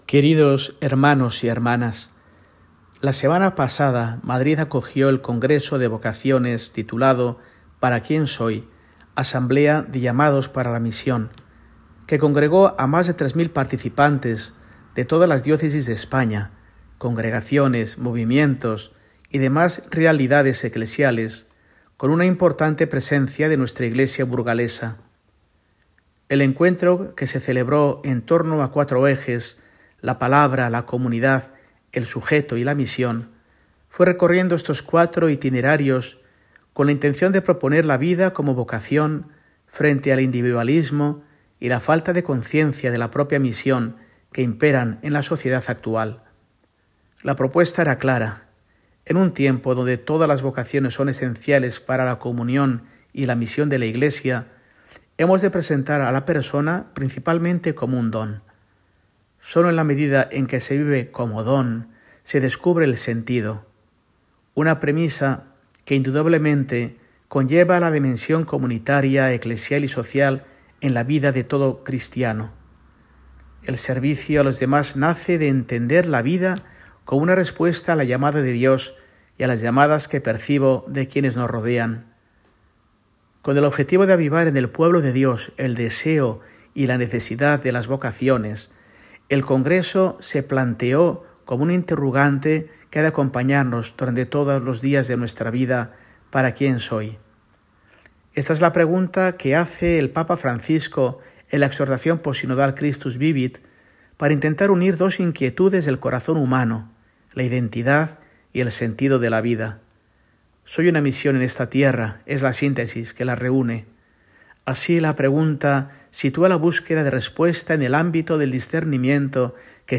Mensaje semanal de Mons. Mario Iceta Gavicagogeascoa, arzobispo de Burgos, para el domingo, 16 de febrero de 2025, VI del Tiempo Ordinario